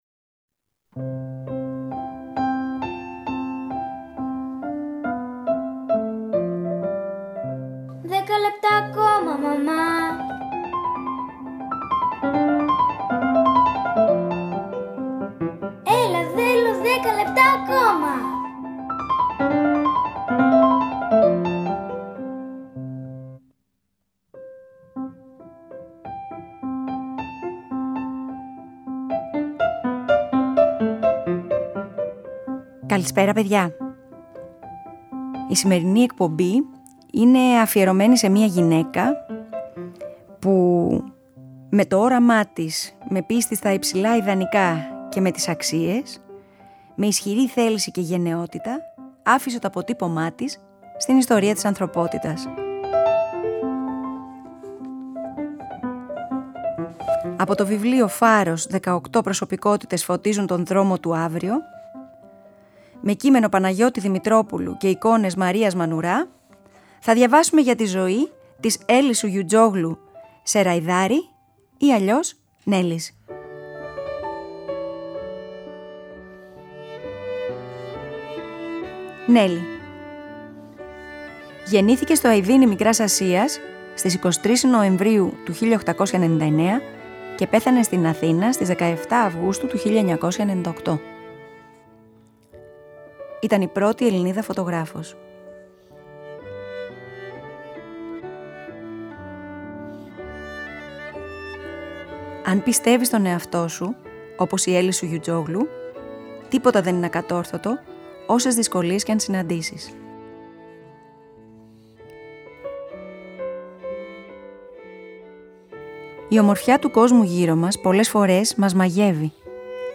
Σήμερα θα διαβάσουμε για μια γυναίκα φωτογράφο που ξεχώρισε για τον λυρισμό της.